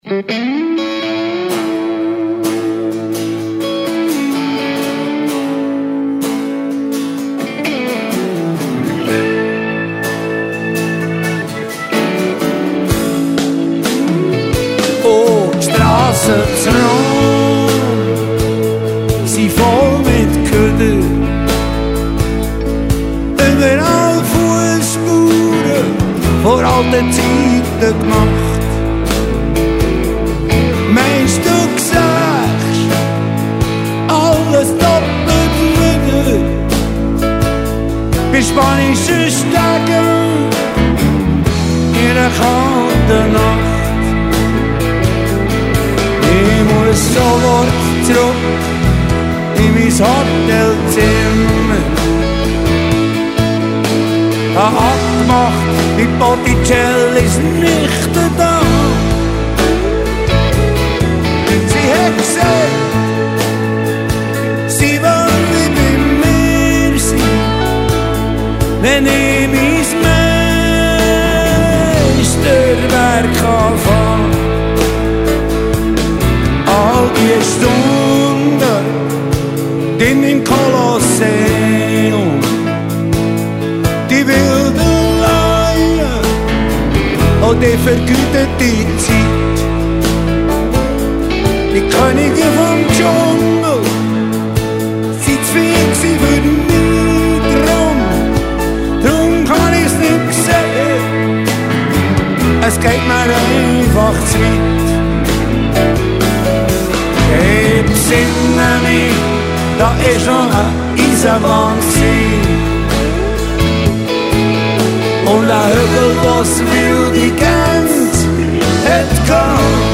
Swiss-German dialect